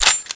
assets/ctr/nzportable/nzp/sounds/weapons/ppsh/boltrelease.wav at 9ea766f1c2ff1baf68fe27859b7e5b52b329afea
boltrelease.wav